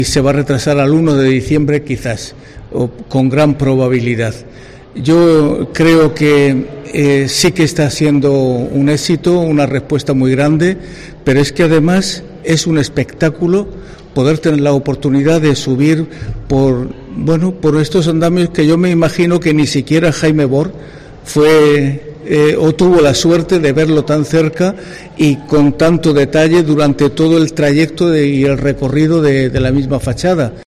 Monseñor Lorca Planes, obispo de la Diócesis de Cartagena